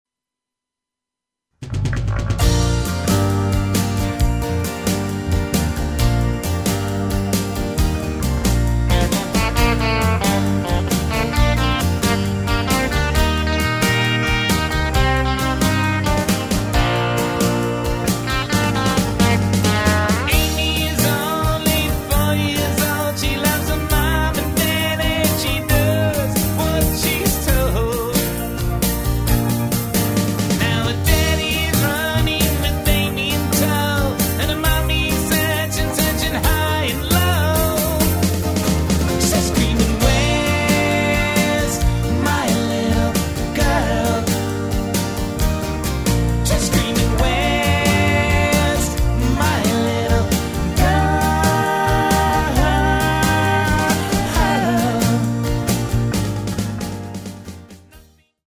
lead & harmony vocals, Fender Precision bass
Neo-Americana music for the New Millennium